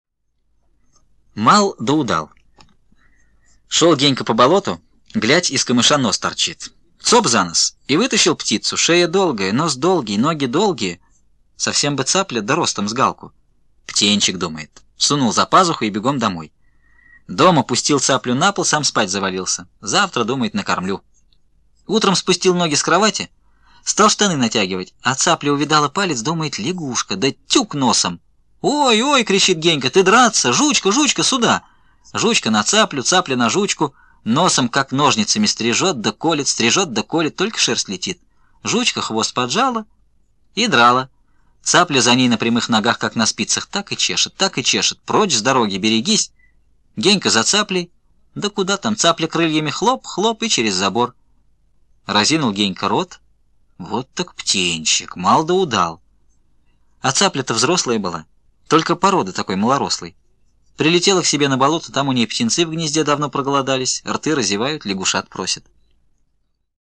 Аудиорассказ «Мал, да удал»